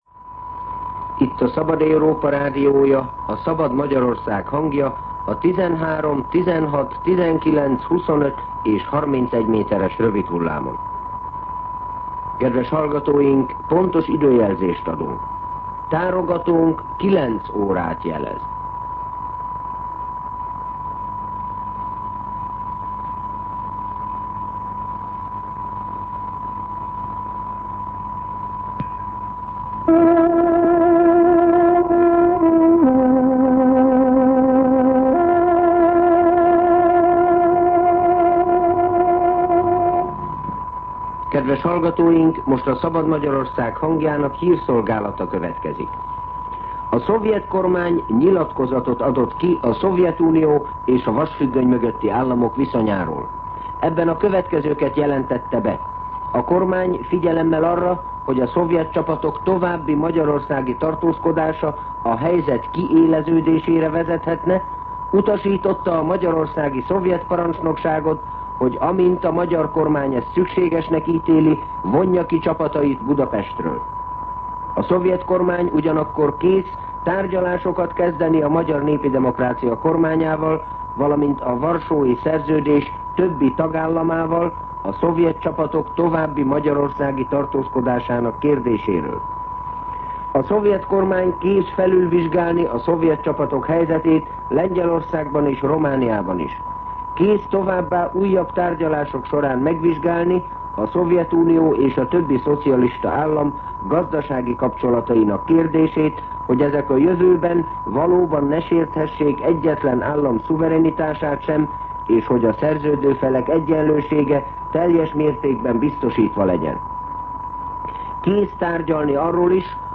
09:00 óra. Hírszolgálat